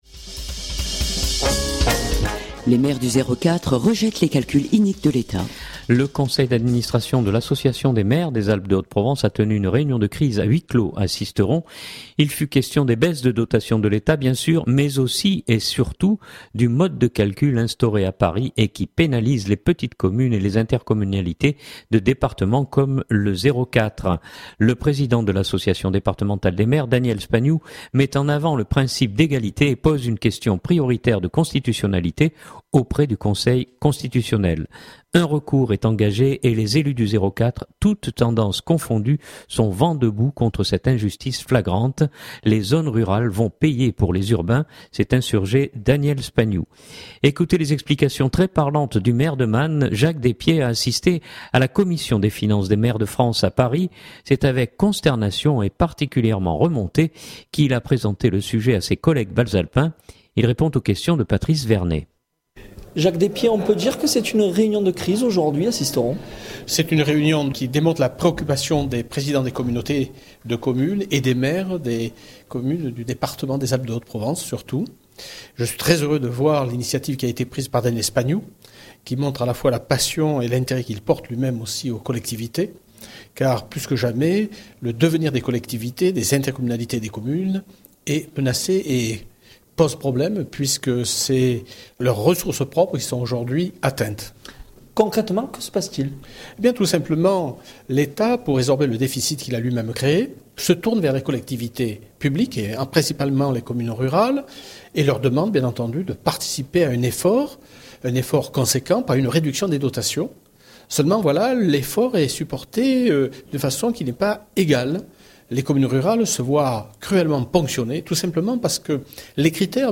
C’est avec consternation et particulièrement remonté qu’il a présenté le sujet à ses collègues bas-alpins.